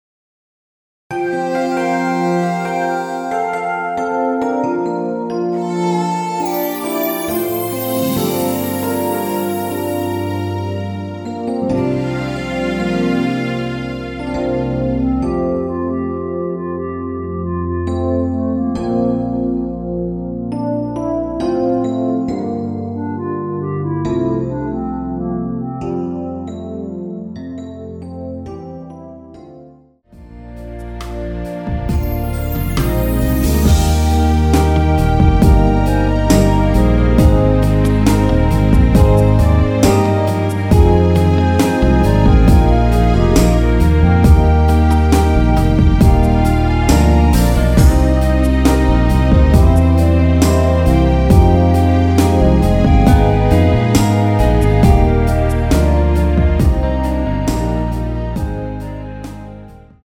원키에서(-2) 내린 멜로디 포함된 MR입니다.
앞부분30초, 뒷부분30초씩 편집해서 올려 드리고 있습니다.